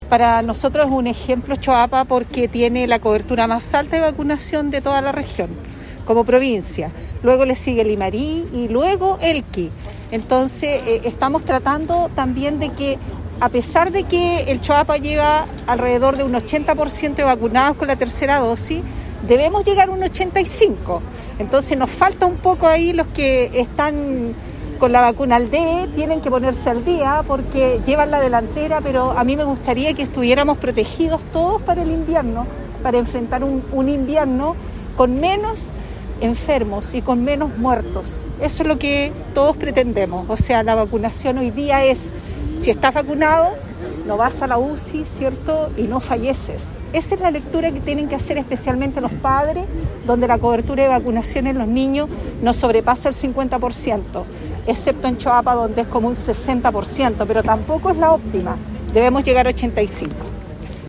Luego de finalizar el Gabinete Regional en terreno, realizado este lunes en la comuna de Salamanca, autoridades regionales visitaron un punto de vacunación, donde reforzaron el llamado a la población a completar sus esquemas de inoculación contra el Covid_19 y la Influenza.
De igual manera, la Seremi de Salud Paola Salas, puntualizó la importancia de la protección de la vacuna y el impacto en la reducción de la letalidad.